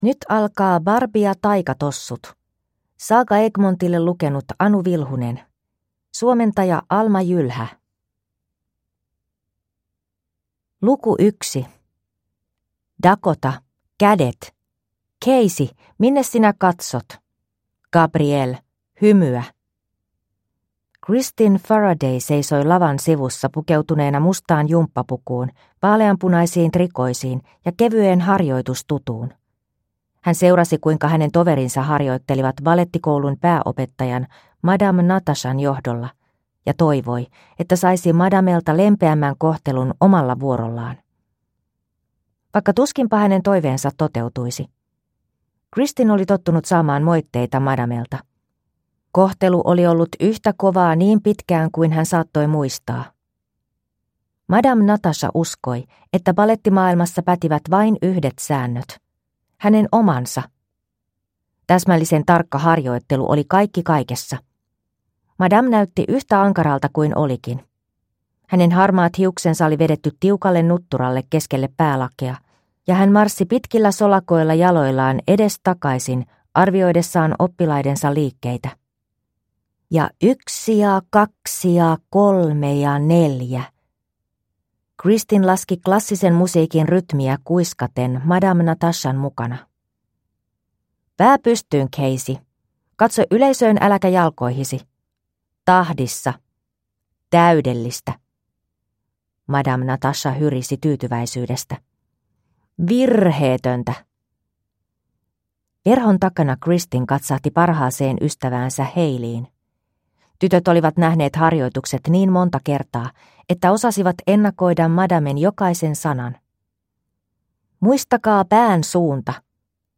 Barbie ja taikatossut (ljudbok) av Mattel